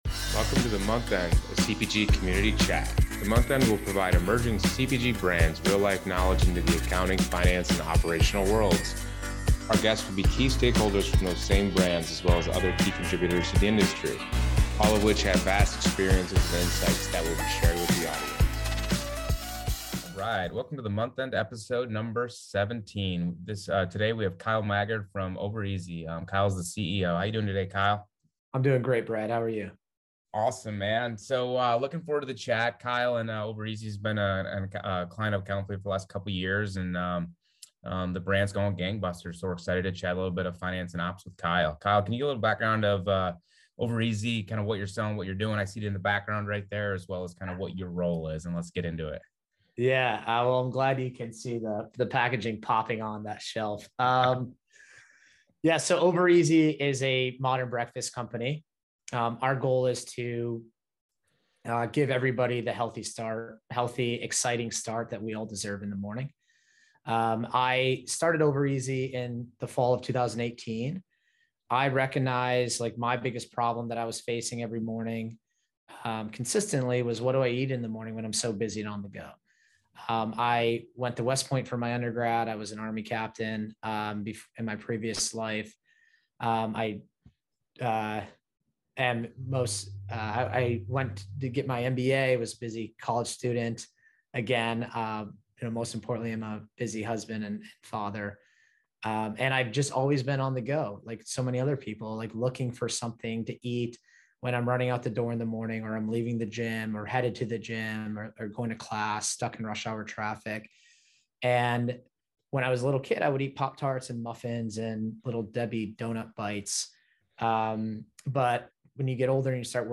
Each episode provides a glimpse into the vast experience and insight from its guest’s unique background in a casual, conversational tone.